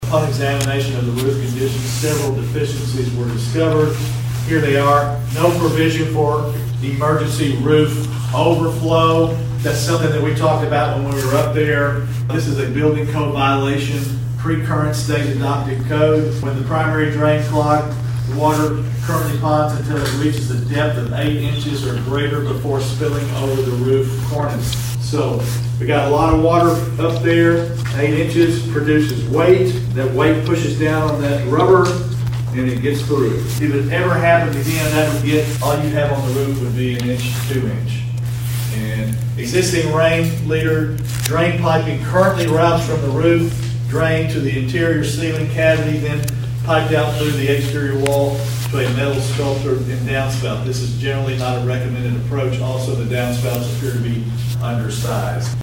Martin Mayor David Belote shared those areas with the Finance Committee at this month’s meeting.